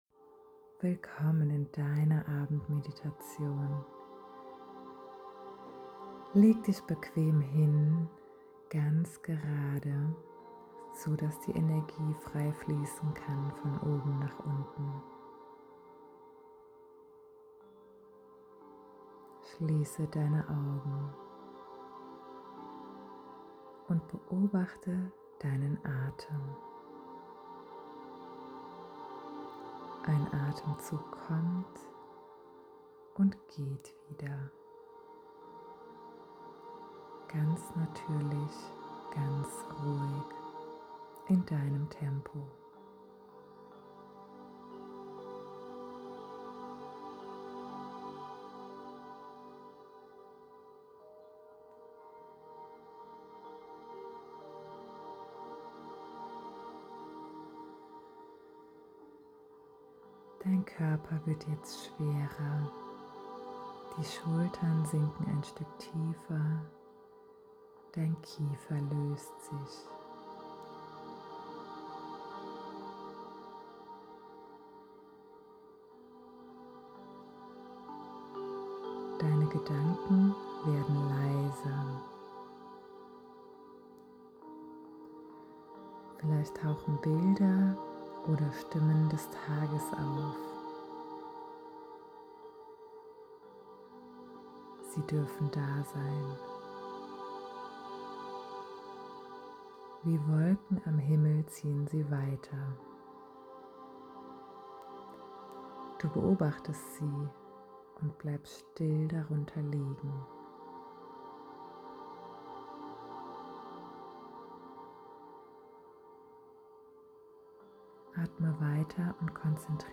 Abendmedition
Abendmeditation-1.mp3